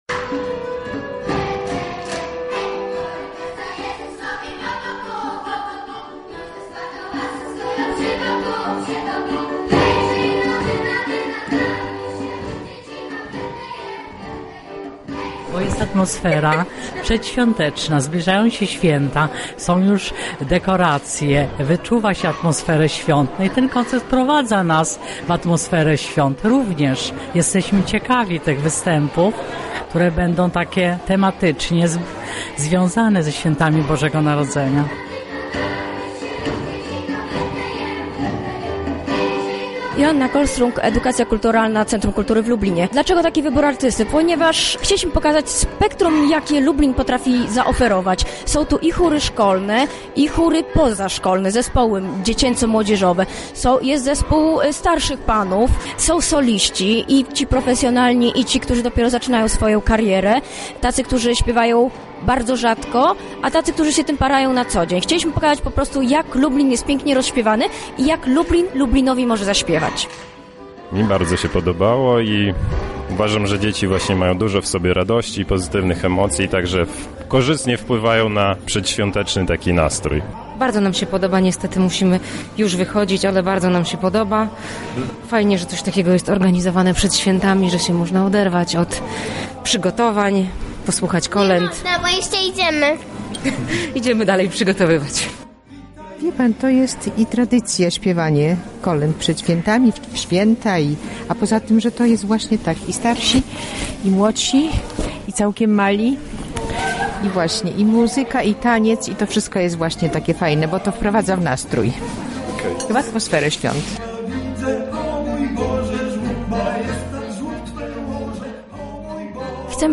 W Lublinie odbył się koncert kolęd, w którym zaprezentowano różnorodne style i formacje. W czasie wydarzenia usłyszeliśmy solistów, chóry, dorosłych i dzieci.